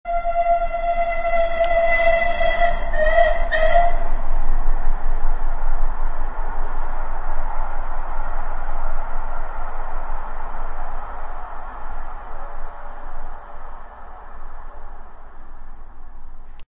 Download Train Whistle Blowing sound effect for free.
Train Whistle Blowing